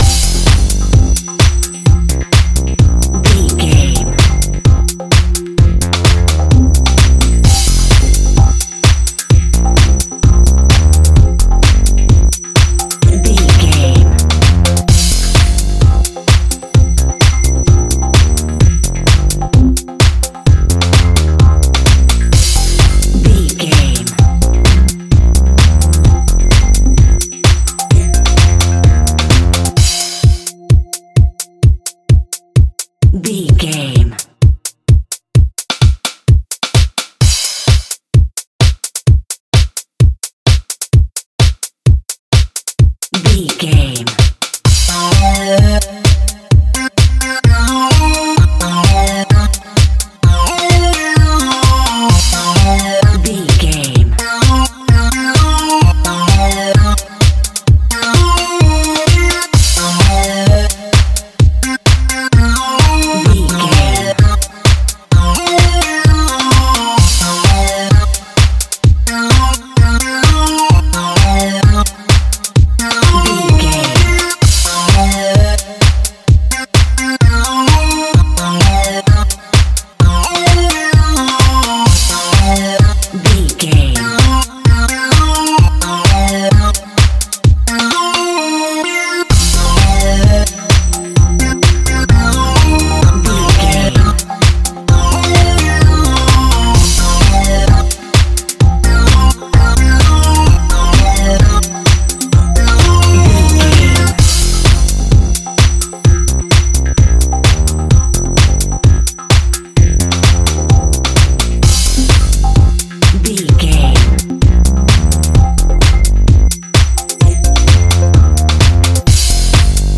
Funky Deep House.
Aeolian/Minor
groovy
futuristic
uplifting
synthesiser
drum machine
electro house
synth pop
joyful
keyboards